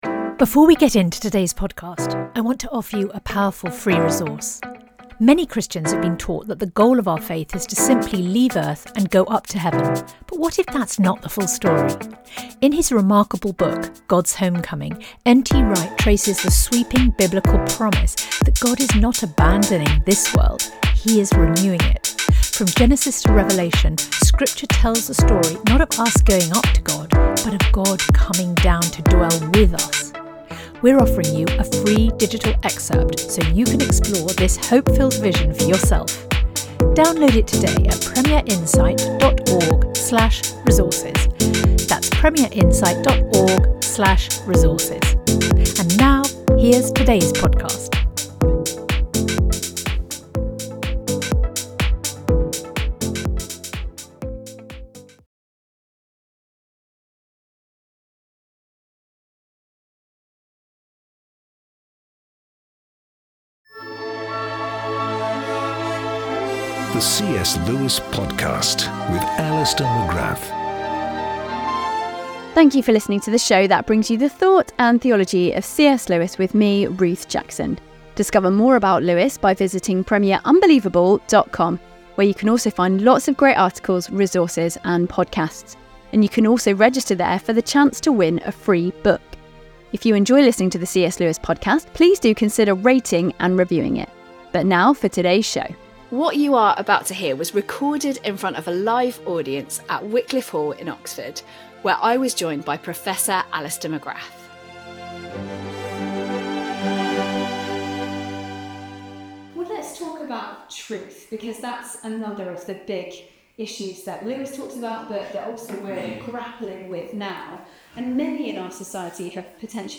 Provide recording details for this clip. live event recording of the CS Lewis Podcast, captured in front of an audience